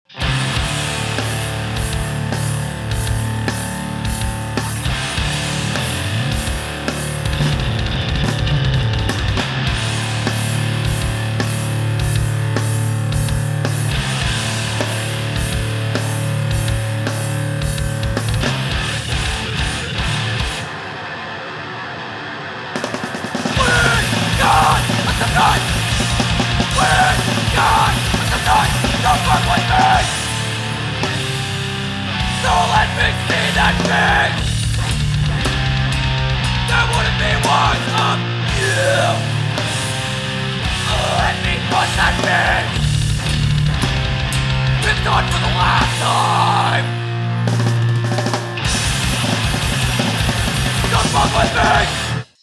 Loud and heavy music
Punk Rock Music